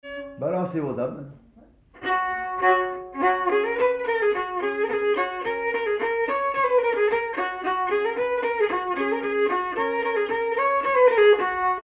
Mazurka partie 2
danse : mazurka
circonstance : bal, dancerie
Pièce musicale inédite